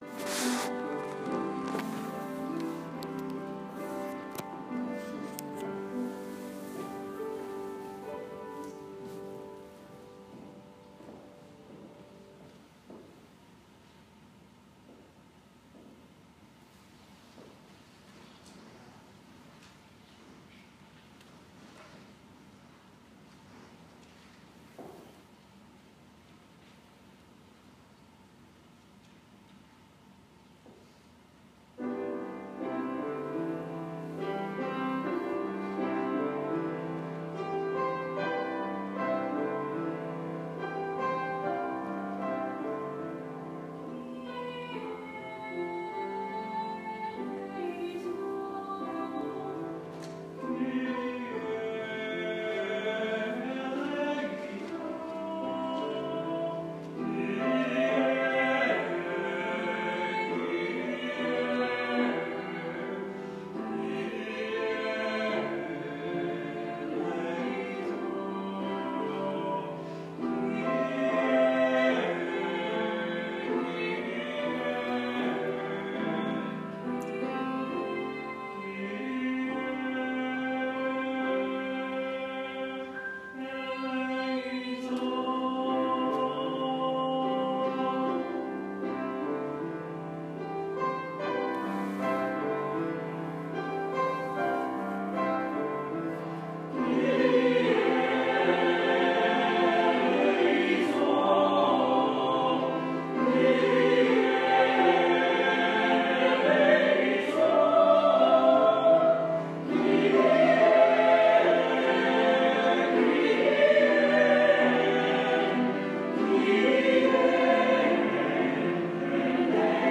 4월 24일 주일 찬양대(주여, Jay Althouse곡)